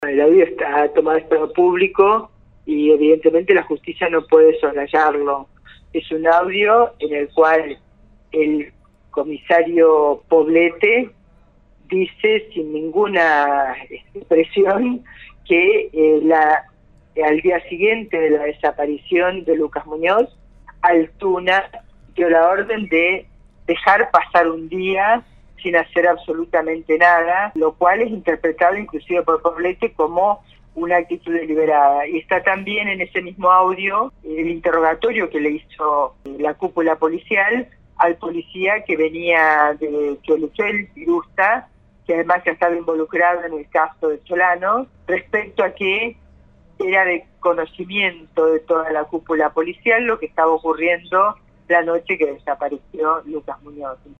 La Diputada Nacional marco posición con respecto al audio y resalto la gravedad de la situacion.